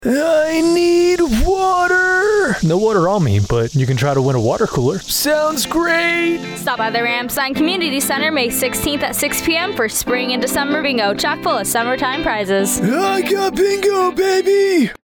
Radio Spot - Summer Bingo